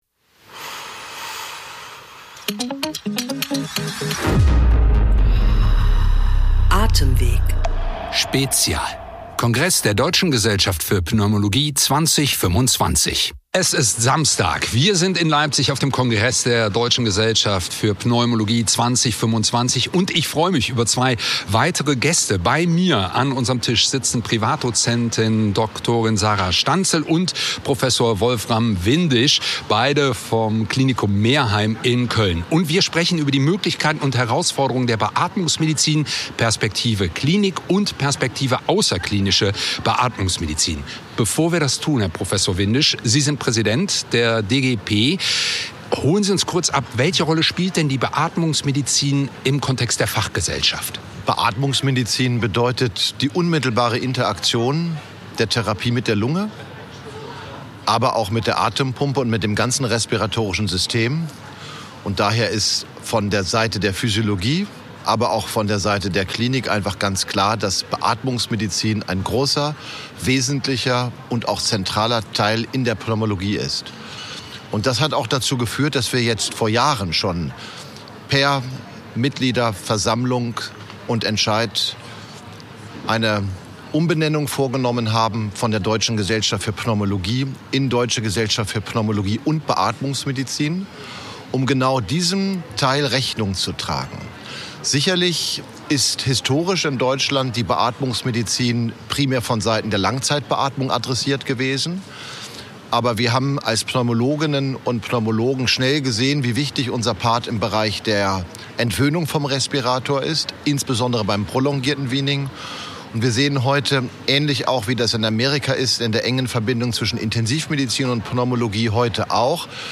Direkt vom 65. DGP-Kongress in Leipzig: In unserer dreiteiligen Podcastreihe sprechen wir mit renommierten Expert*innen über die wichtigsten Themen der Pneumologie – aufgenommen auf den Fluren des Congress Center Leipzig.